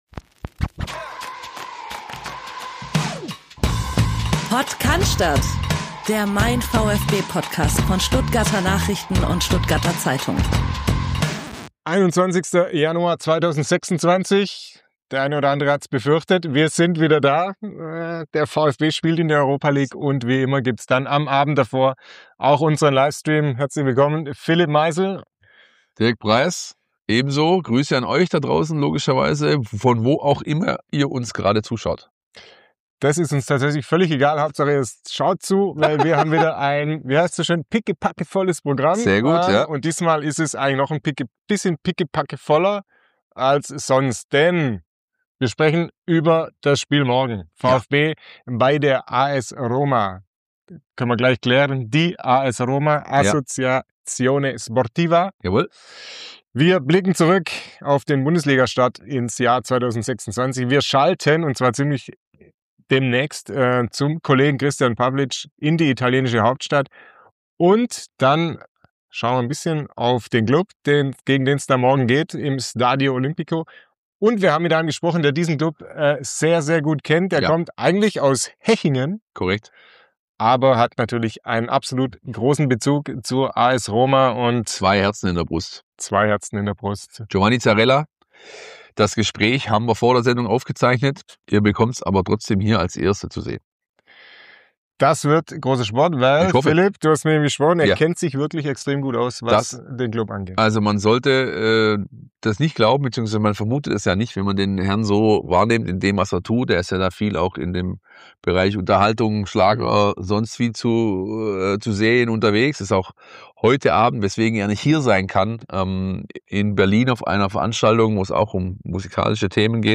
Es handelt sich um ein Audio-Re-Live des YouTube-Streams von MeinVfB.